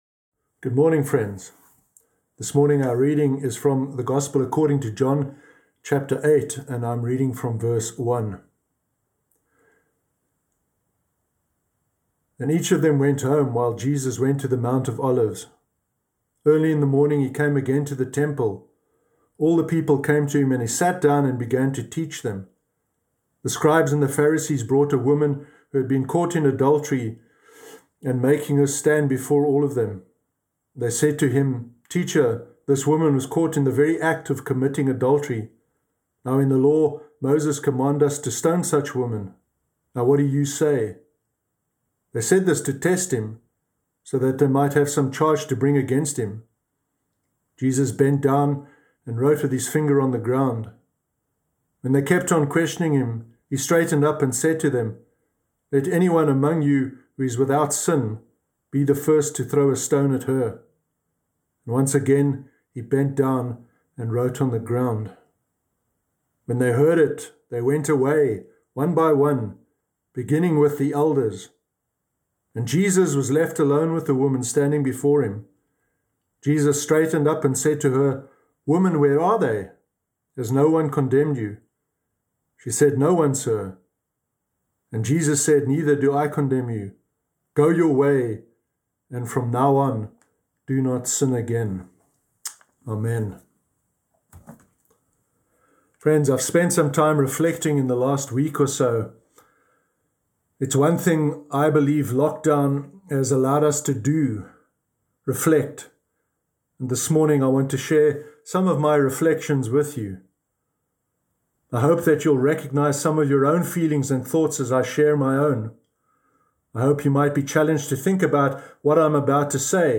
Sermon Sunday 14 February 2021